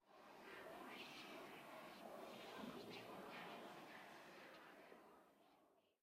Minecraft Version Minecraft Version latest Latest Release | Latest Snapshot latest / assets / minecraft / sounds / ambient / nether / soulsand_valley / whisper5.ogg Compare With Compare With Latest Release | Latest Snapshot
whisper5.ogg